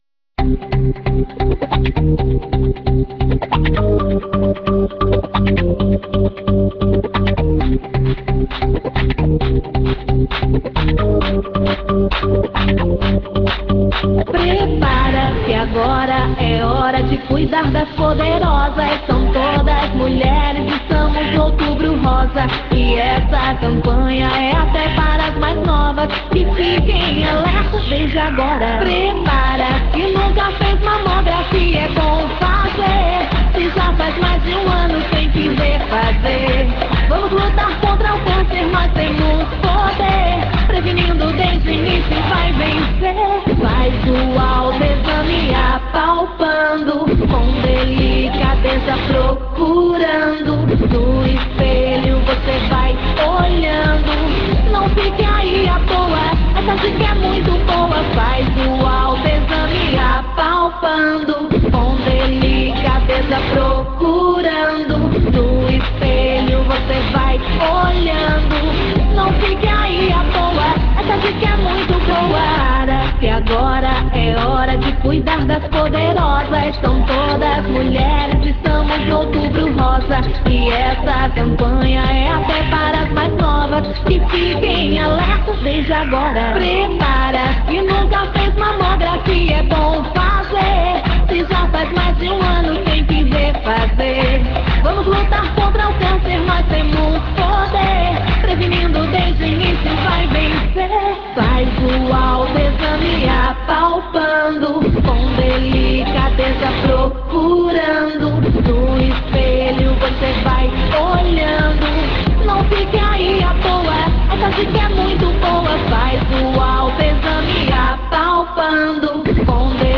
Música da Campanha do Câncer de mama